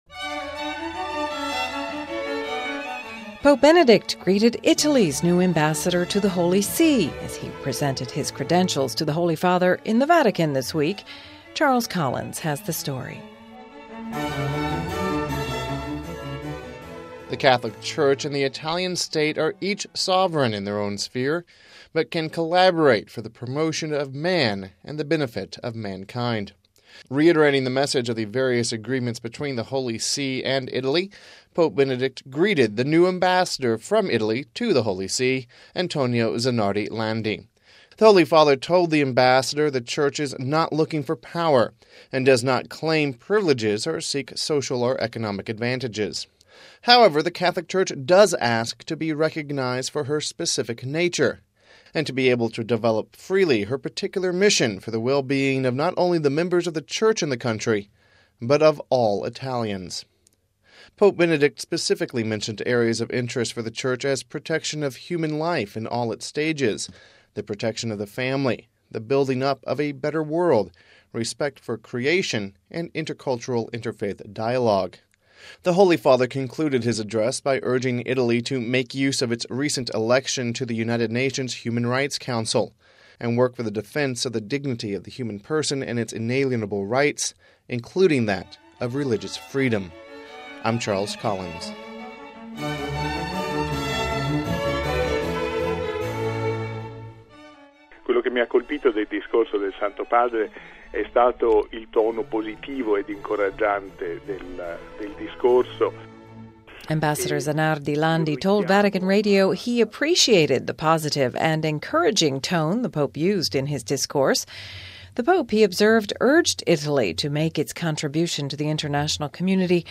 Home Archivio 2007-10-05 14:10:38 POPE GREETS AMBASSADOR Pope Benedict XVI welcomes Italy’s new ambassador to the Holy See, Antonio Zanardi Landi as he presents his letters of credence All the contents on this site are copyrighted ©.